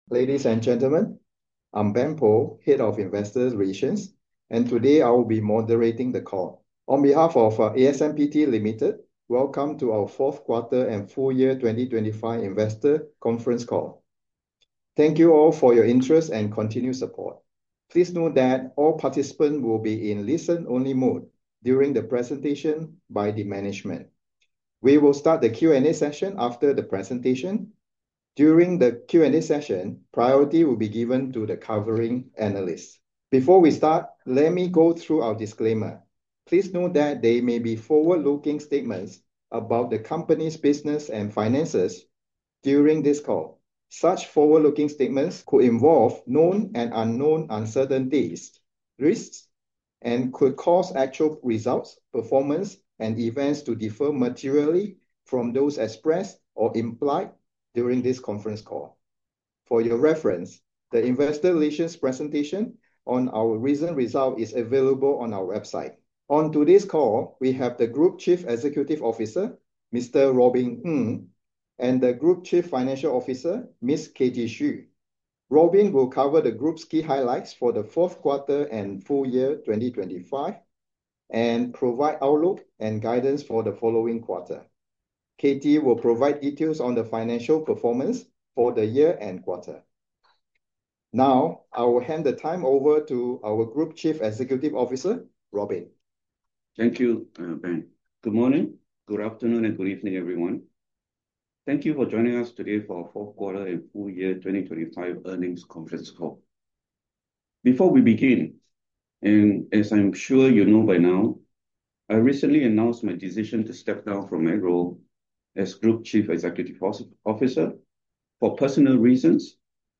asmpt_q4_fy2025_investor_conference_call_audio.mp3